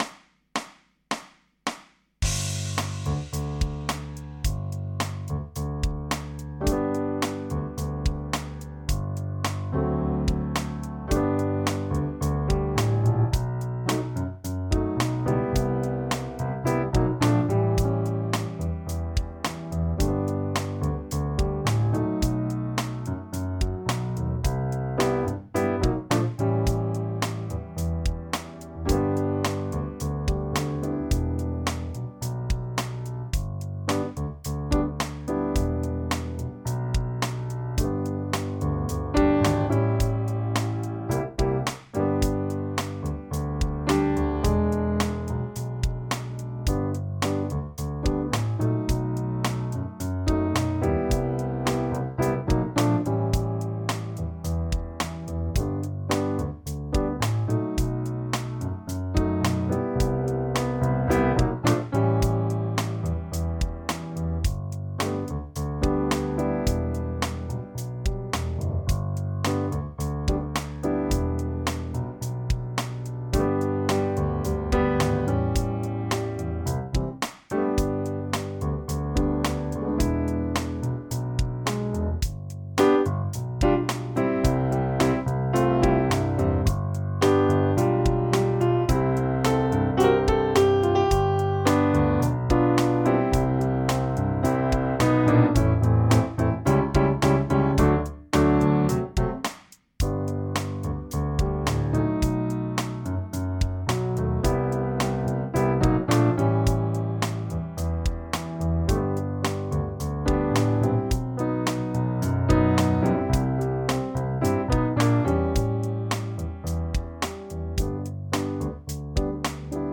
Übungsaufnahmen - Ebay
Runterladen (Mit rechter Maustaste anklicken, Menübefehl auswählen)   Ebay (Playback)
Ebay__5_Playback.mp3